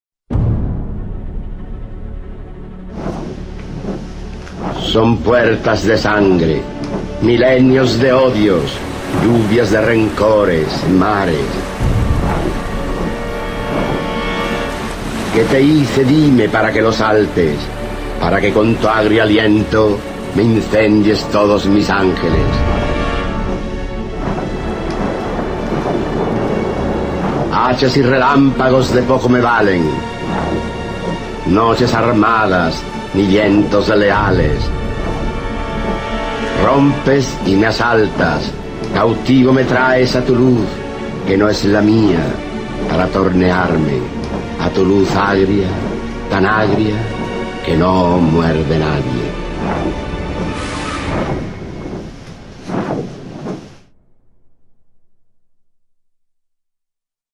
Archivo de sonido con la voz del escritor español Rafael Alberti, quien recita su poema “El ángel rabioso" (Sobre los ángeles, 1927-1928).
Se recomienda este recurso para promover un encuentro placentero de los estudiantes con el texto literario, recitado en la voz de su autor.